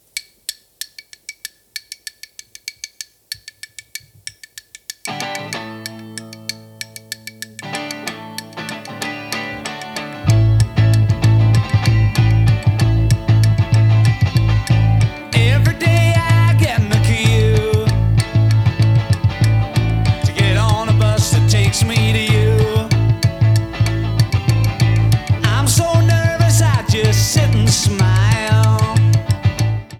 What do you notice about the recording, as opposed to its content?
Stereo) (Alternate version